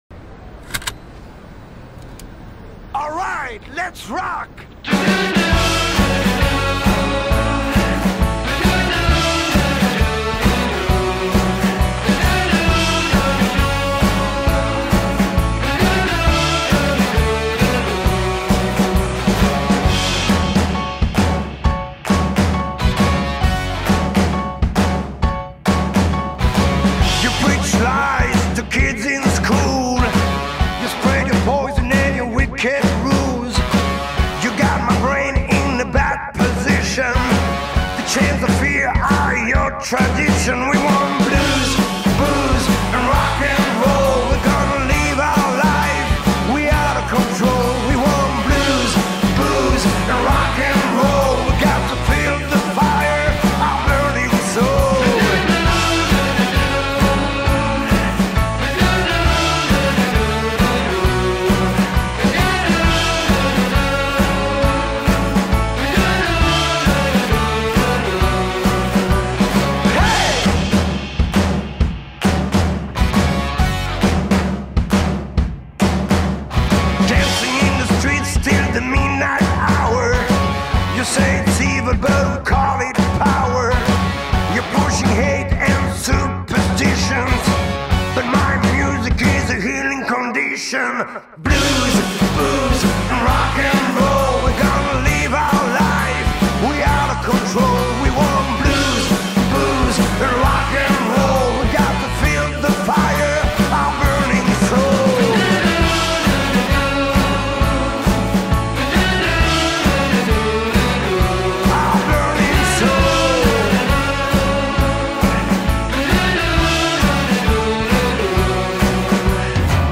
Figure emblématique du blues-rock en France
à la fois chanteur guitariste et showman exceptionnel.